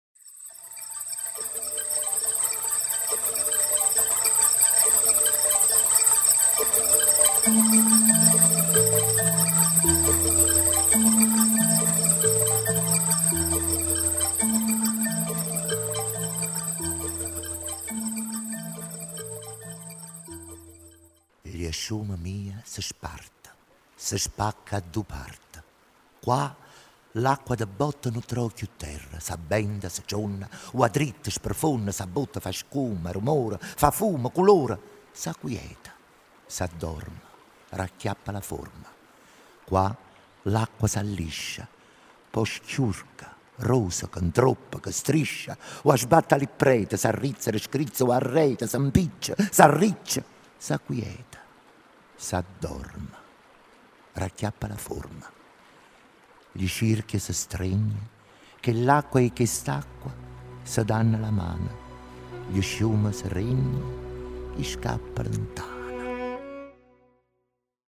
Clicca qui per ascoltare la poesia "Gl' sciume mia" (di Neno Pisani) nella recitazione di Peppe Barra